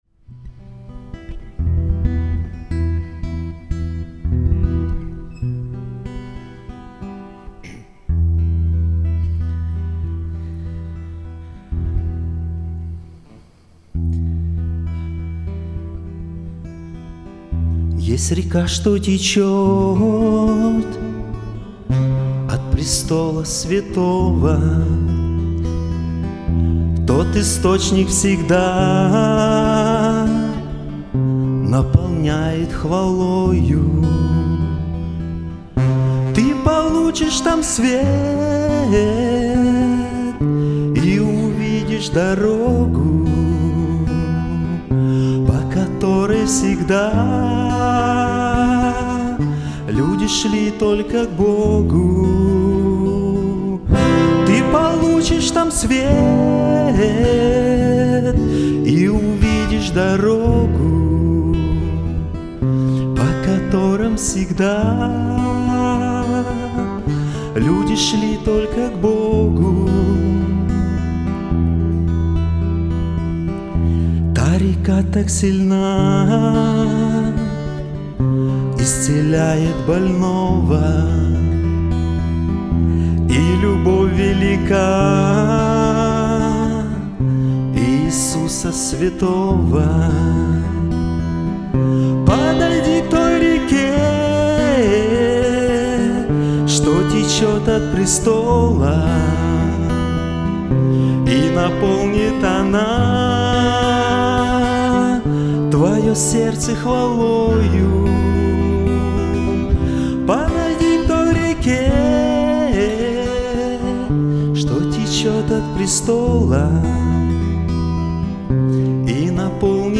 06-12-22 Воскресение — Церковь «Путь ко Спасению»
06-12-22 Воскресение 7:53 01 Проповедь 14:29 02 Проповедь 2 3:20 03 Хор 5:02 04 Пение 5:03 05 Пение 2 4:05 06 Пение 3 20:35 07 Проповедь 3 2:27 08 Хор 2 2:33 09 Пение 4 3:10 10 Пение 5 31:54 11 Проповедь 4